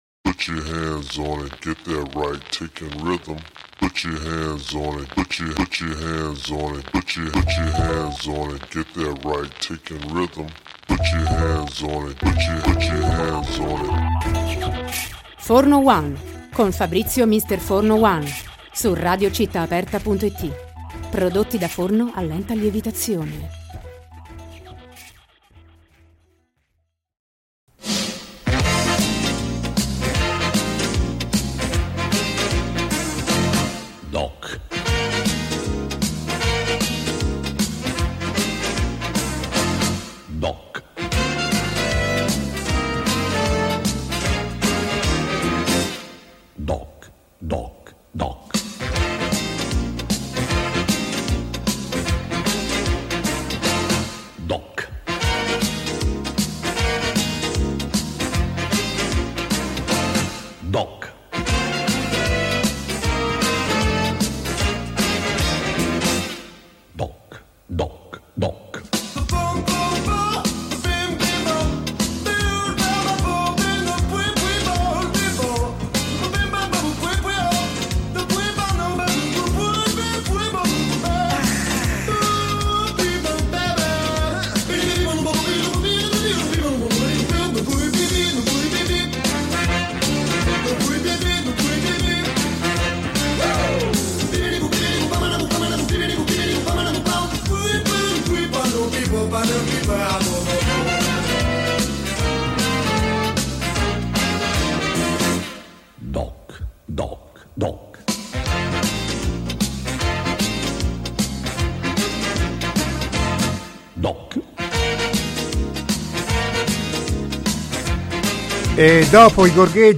Intervista-Soultrend-02-5-24.mp3